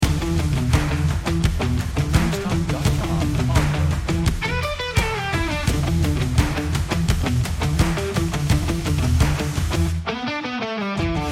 Stomp Rock